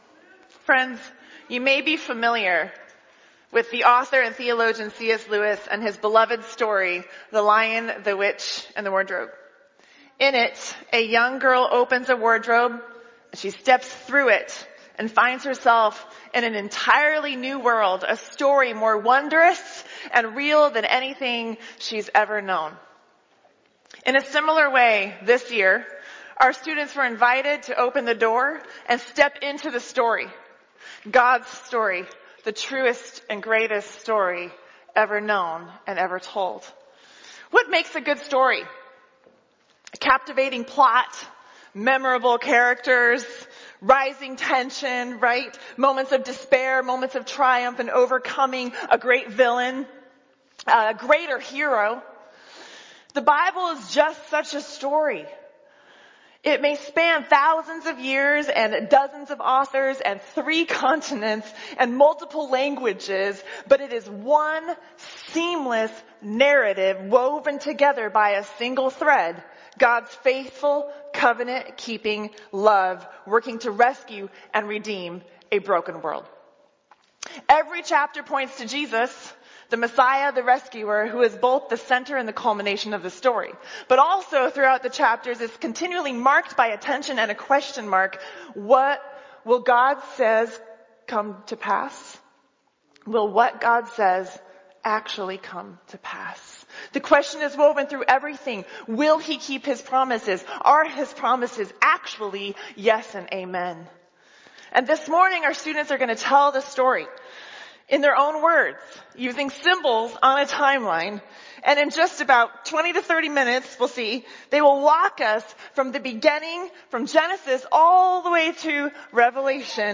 Confirmation Sunday